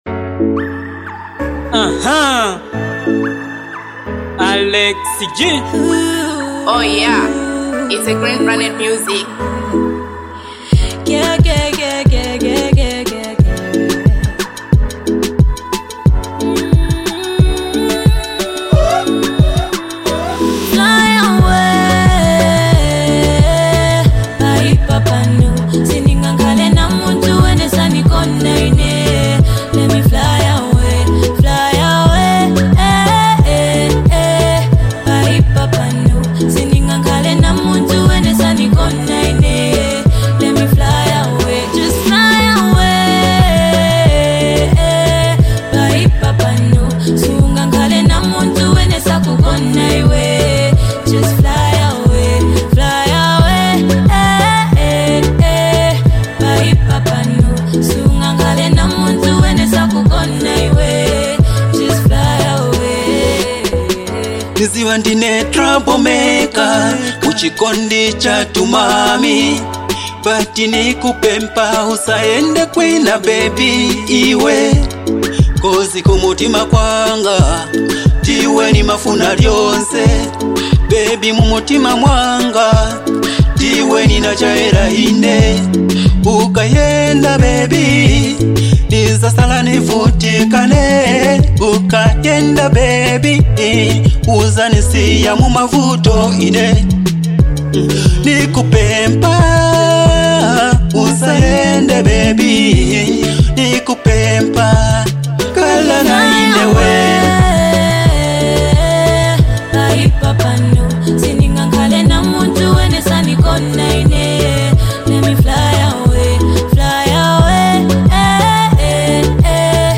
a smooth, emotional track about escaping pain